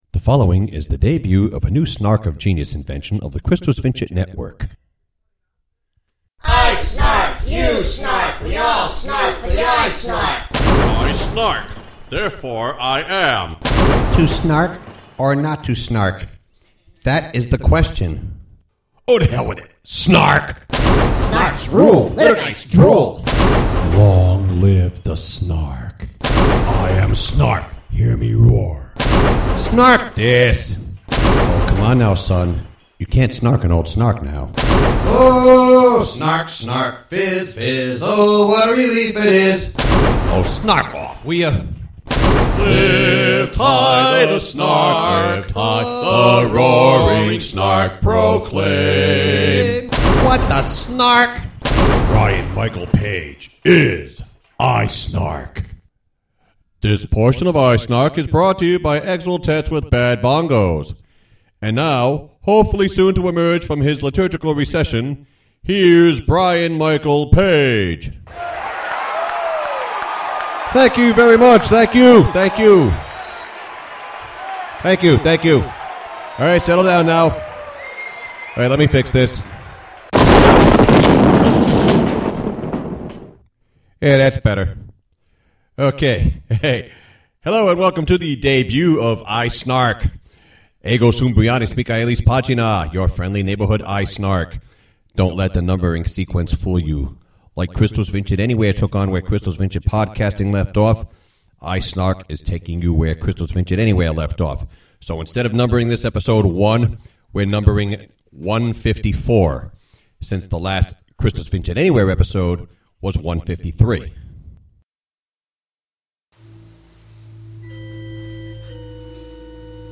We've also added a new voice to the mix, Basso Profundo, the Singing Fish ("Basso" rhymes with "Lasso"; after all, he IS a fish).